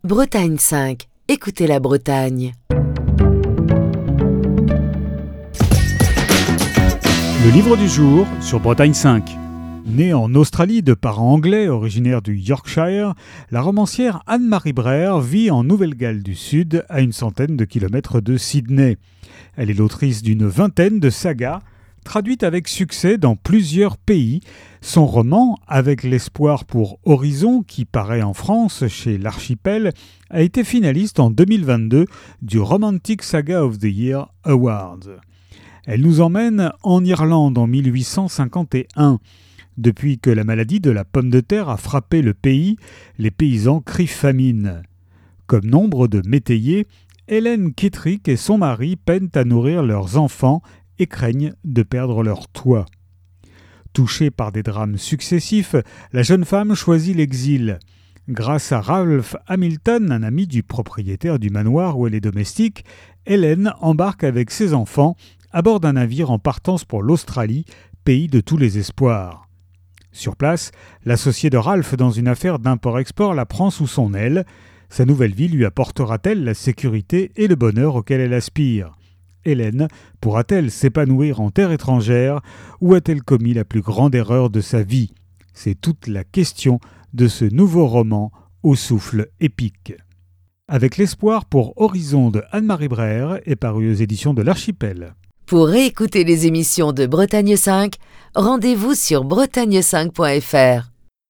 Chronique du 23 septembre 2024.